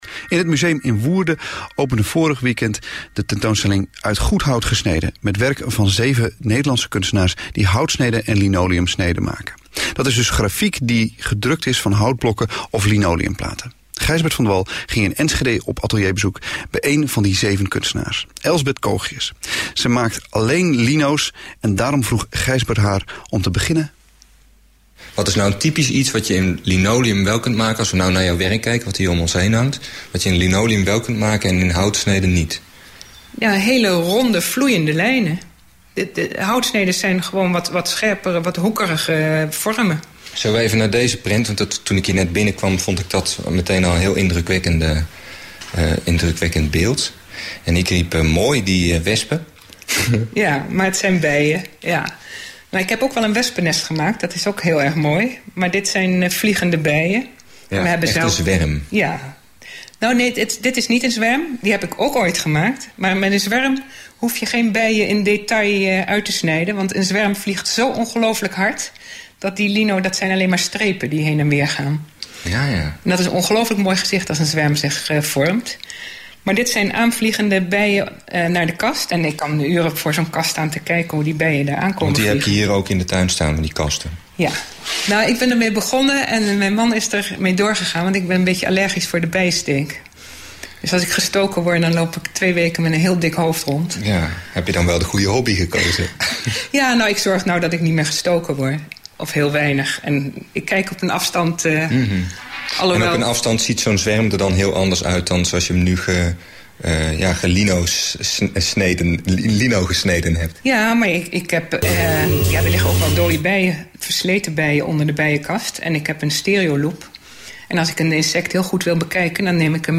Interview in De Avonden(vpro)